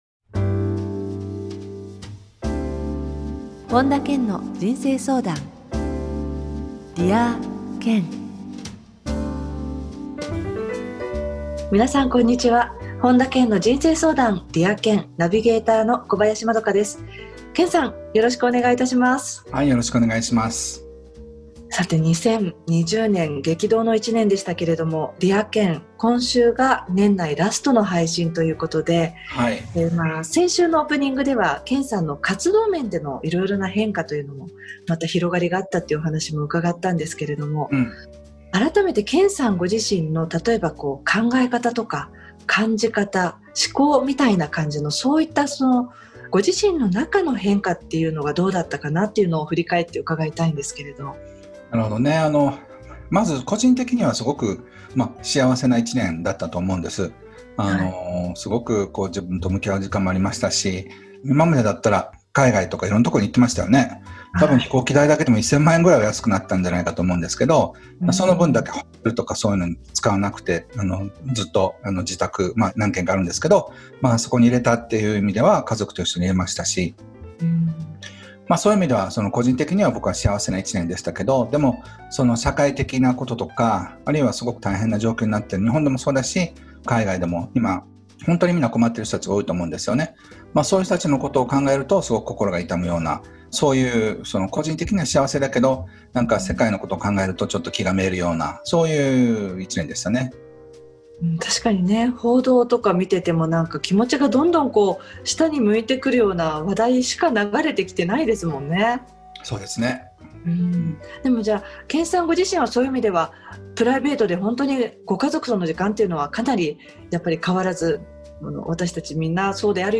本田健の人生相談 ～Dear Ken～ 傑作選 今回は「心を満たす方法」をテーマに、本田健のラジオミニセミナーをお届けします。